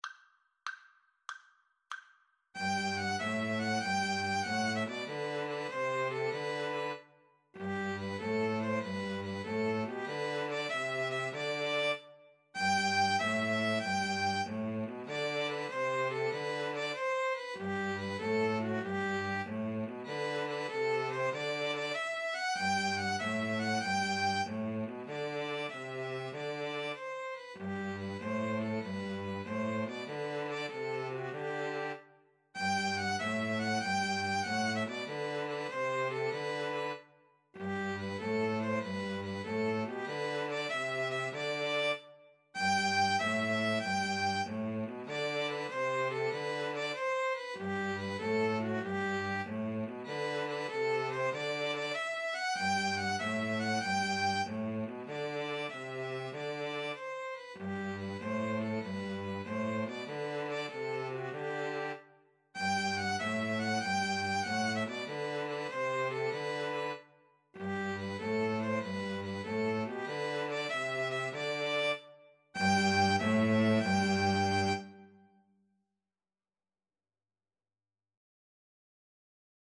Classical Trad. Sumer is icumen in (Summer is a-coming in) String trio version
3/8 (View more 3/8 Music)
G major (Sounding Pitch) (View more G major Music for String trio )
Happily .=c.96